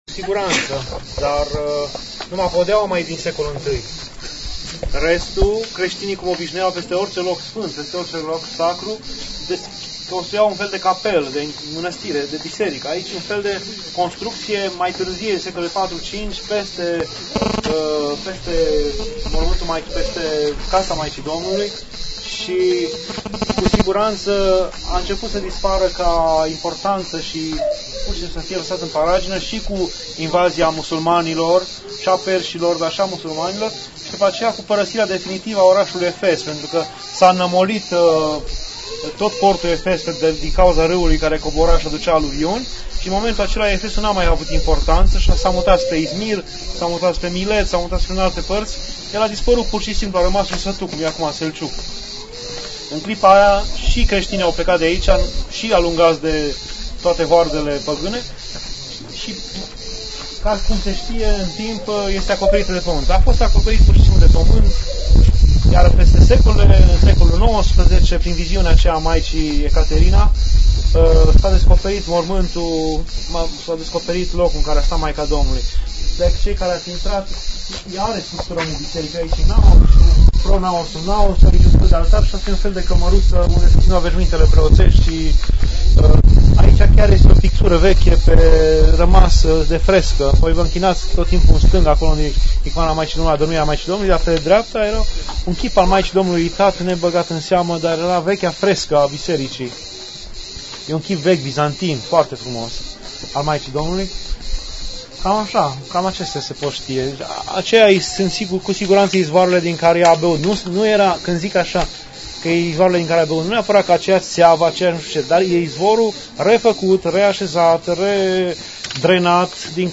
Pelerinaj 2 Turcia, Grecia